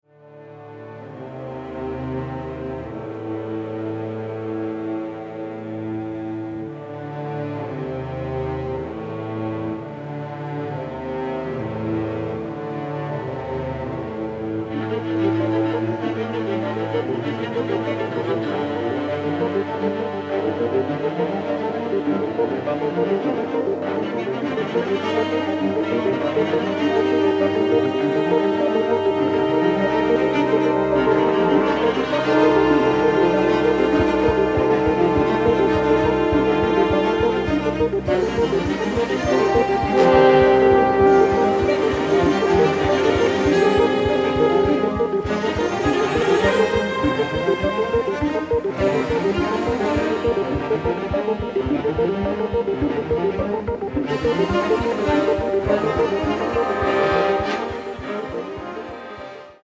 romantic, innovative, masterful score